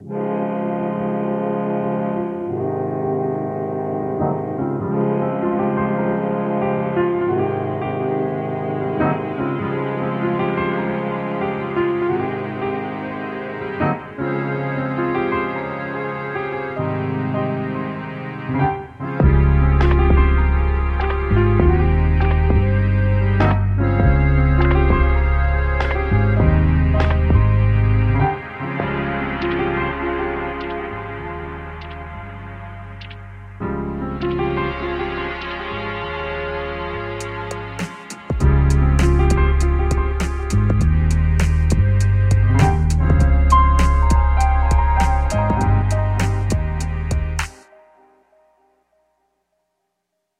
如果您喜欢老式乐器或簧片爱好者，该库包含全面的声音和样本集合，展示了 BN8 的全部功能，从深沉而有力的低音音符到明亮闪闪发光的高音。
为了获得详细而逼真的体验，我们还采样了电子琴的特征风扇噪音，当然还有旧键的咔嗒声。
为了使复古的声音更上一层楼，我们还通过模拟盒式磁带录制了所有样本，在录音补丁上增加了个性、温暖和一些晃动的声音。
内置效果包括：空间 – 卷积混响，具有 10 种脉冲响应选项;橱柜模拟;攻击和释放控制;涂抹扩散效应;驱动效应;高通和低通滤波器。